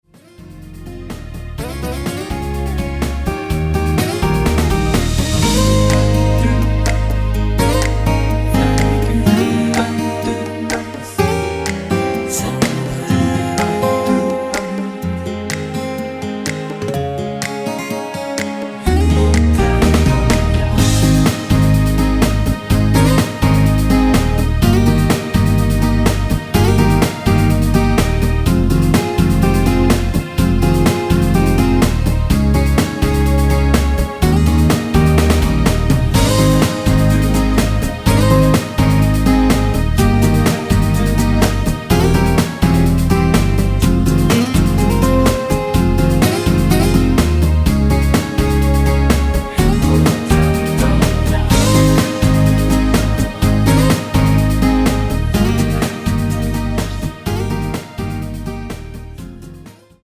코러스 포함된 MR입니다.(미리듣기 참조)
Ab
◈ 곡명 옆 (-1)은 반음 내림, (+1)은 반음 올림 입니다.
앞부분30초, 뒷부분30초씩 편집해서 올려 드리고 있습니다.
중간에 음이 끈어지고 다시 나오는 이유는